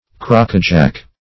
Crackajack \Crack"a*jack`\, n. [Now usually spelled